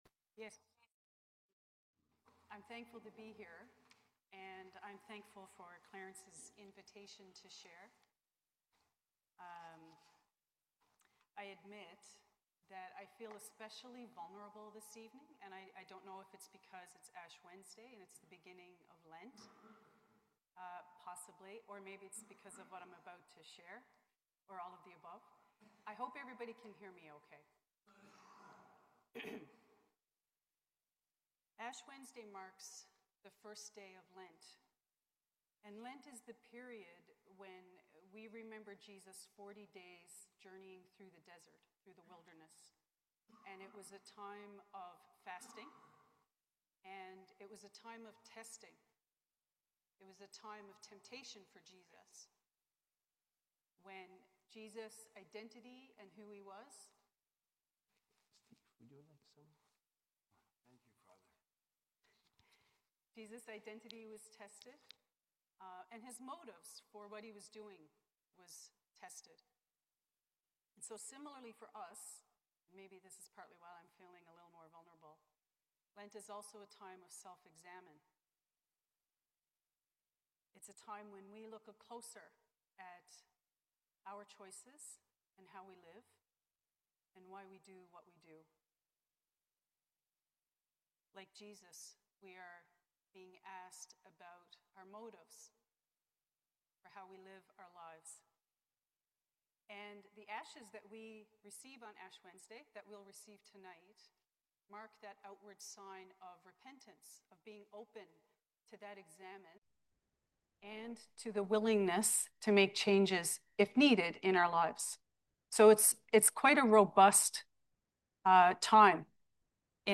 Sermon on Ash Wednesday